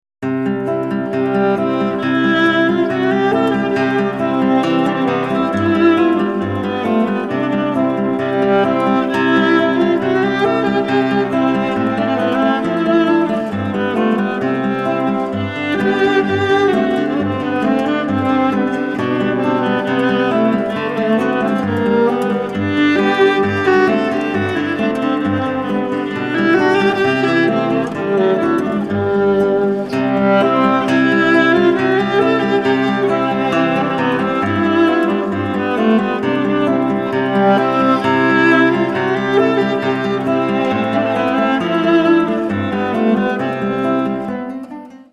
for Viola and Guitar
LISTEN 7 Allegro Moderato